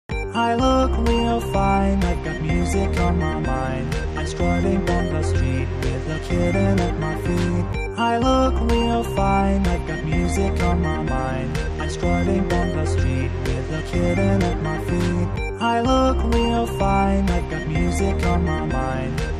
I know the actual join is a little off, but I wanted to keep it short for the sake of the test.